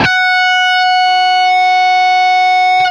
LEAD F#4 CUT.wav